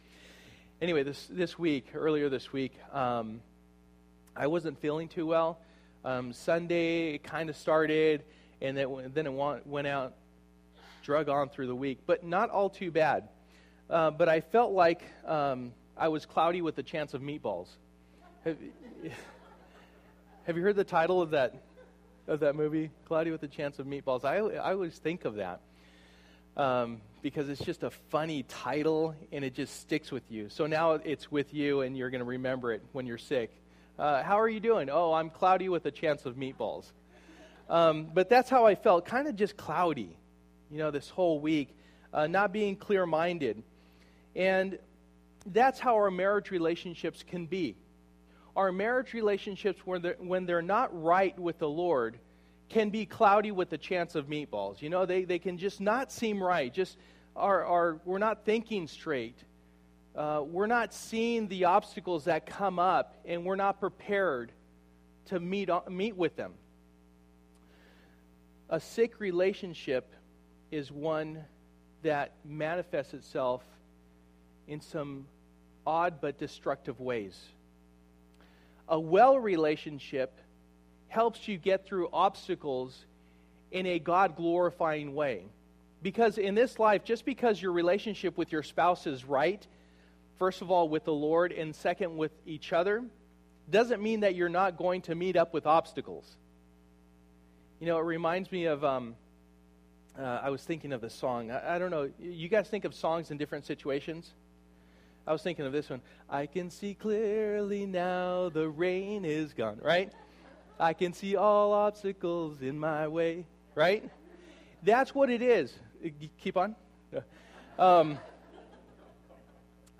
Ephesians 5:33 Service: Sunday Morning %todo_render% « I Do